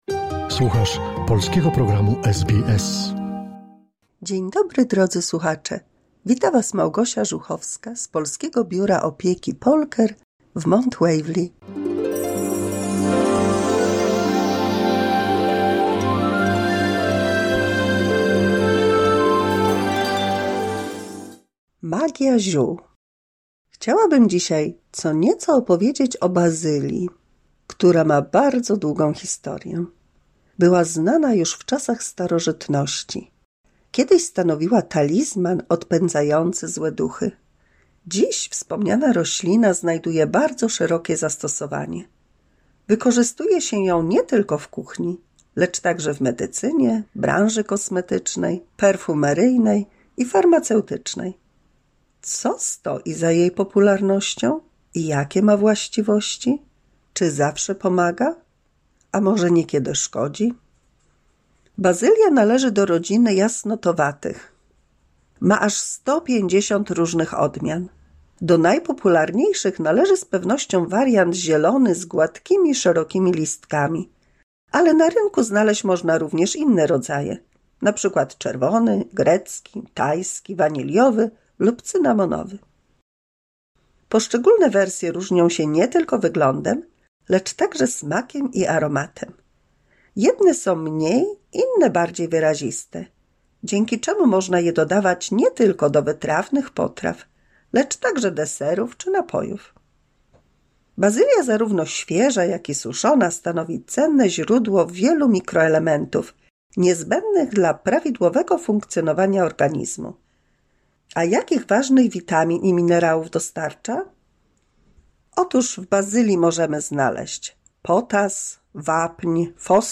172 mini słuchowisko dla polskich seniorów